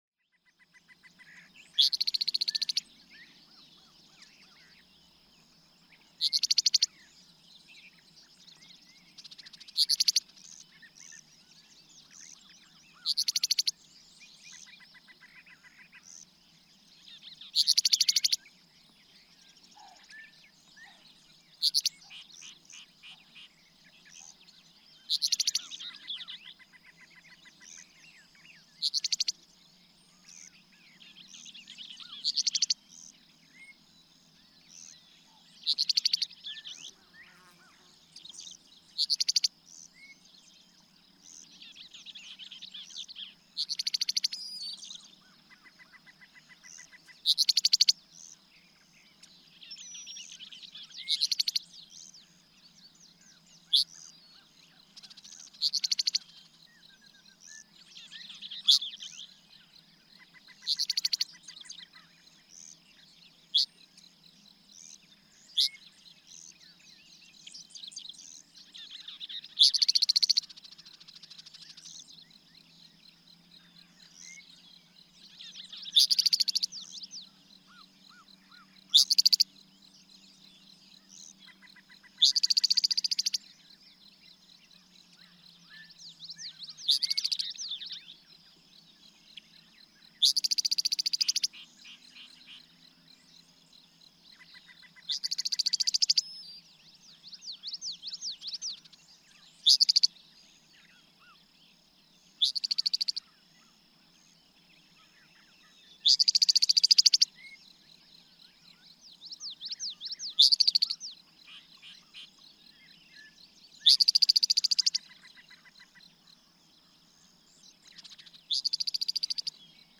Eastern meadowlark: Innate calls are a dzert, or longer dzert-ert-ert-ert-ert-ert.
Mammoth Cave National Park, Kentucky.
618_Eastern_Meadowlark.mp3